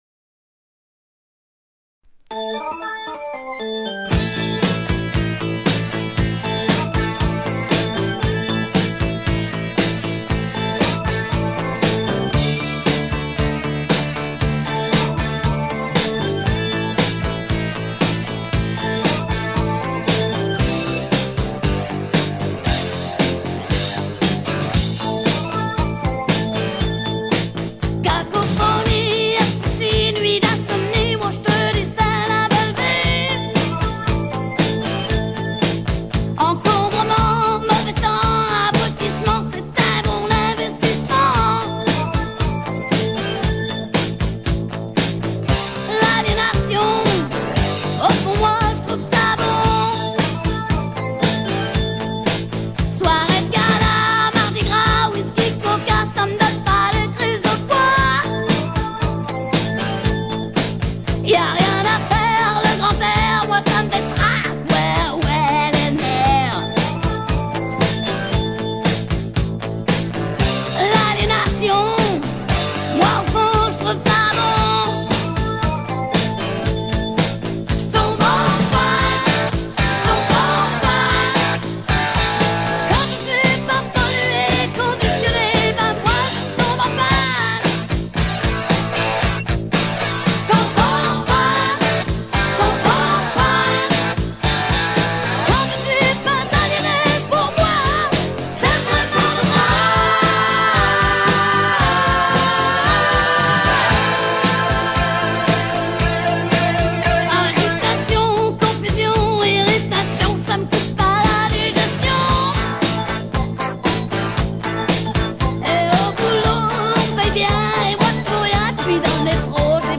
Шикарный голосище!